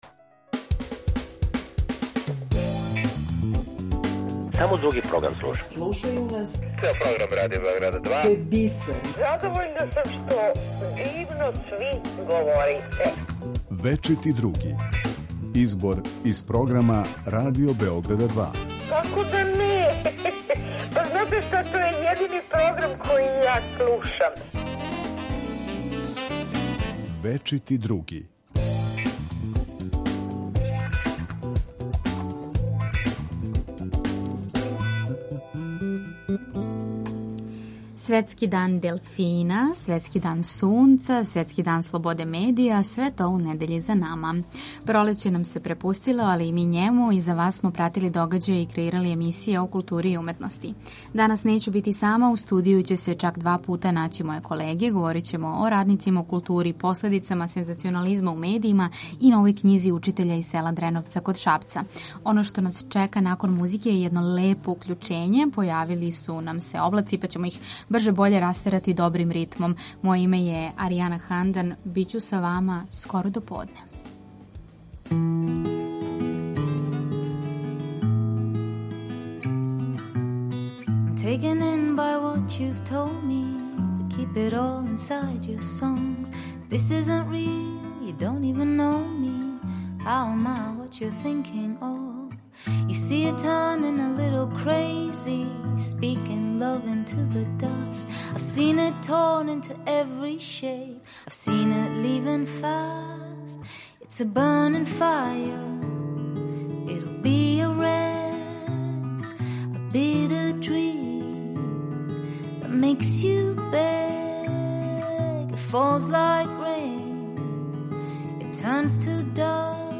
Поред тога што издвајамо делове из прошлонедељног програма, чућемо укључење директно са петнаестог „Kустендорфа"; говорићемо о додели награде за лепоту говора „Радмила Видак"; најавићемо други концерт из циклуса Ансамбли РТС у Галерији, као и неке од емисија које ћете чути на Радио Београду 2.